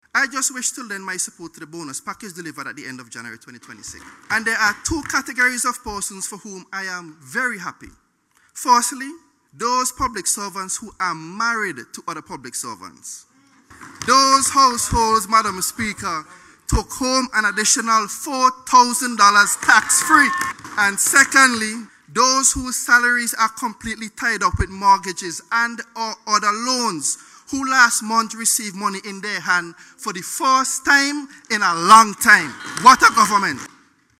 The commendation came from Government Senator and Deputy Speaker of the House of Assembly, Hon. Jamalie John during his contribution to the Budget Debate.